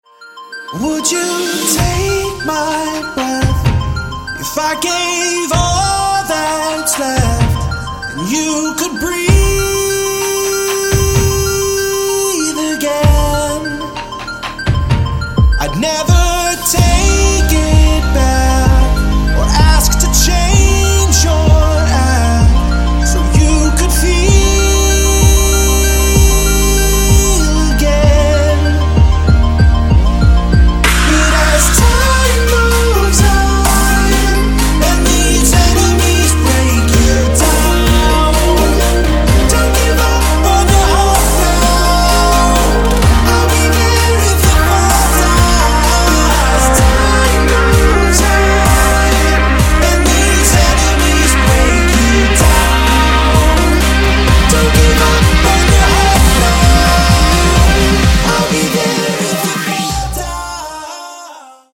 • Качество: 128, Stereo
мужской вокал
Electronic
EDM
club
Melodic
romantic
vocal